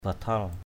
/ba-tʰal/ (d.) lều, nơi trú ẩn.